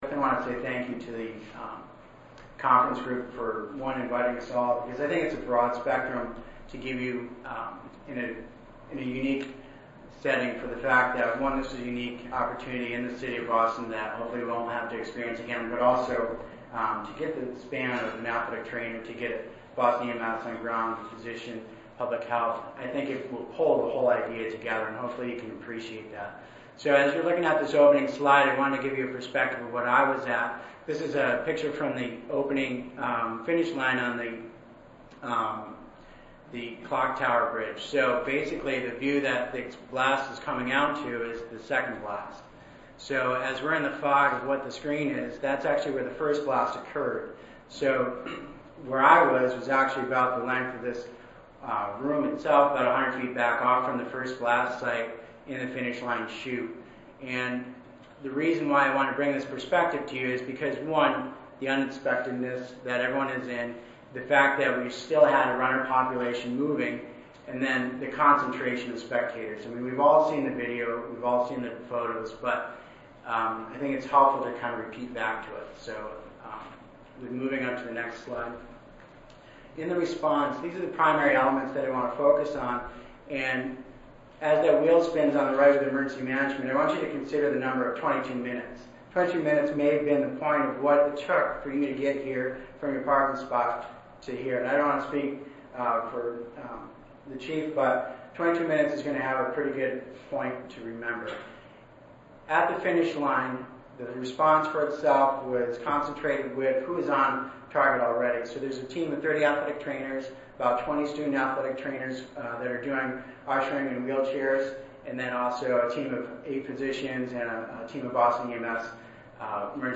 141st APHA Annual Meeting and Exposition
Oral